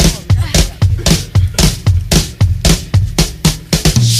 FUNKADELIC DRUM FILL .wav